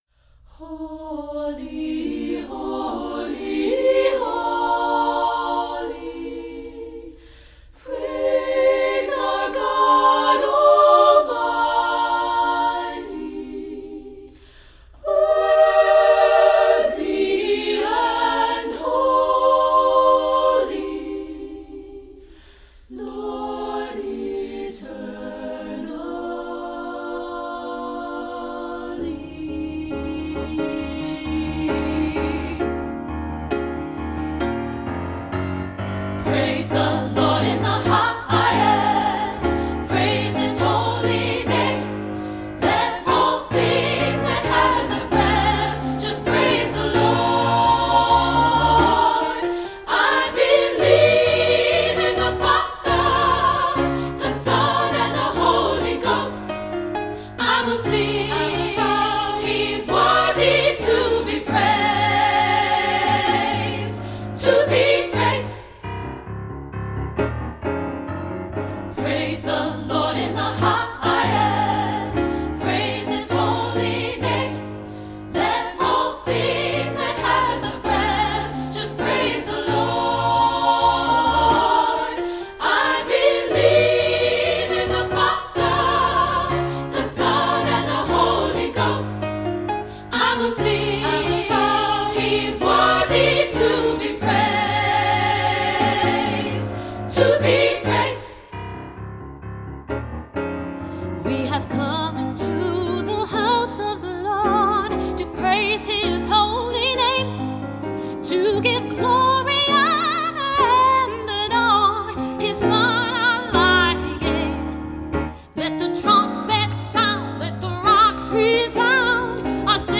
lawson gould choral
- SSA (SSAA recording), sample